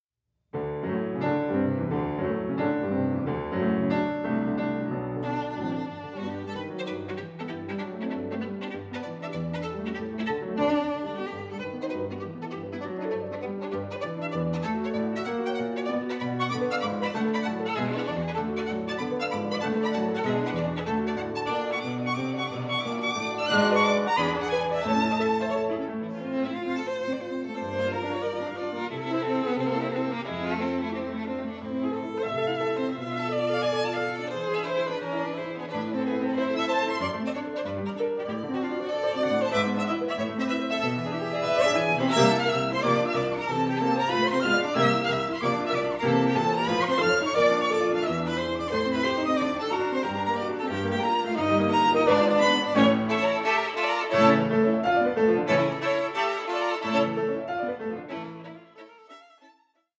Molto vivace 4:52